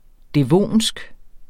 Udtale [ dεˈvoˀnsg ]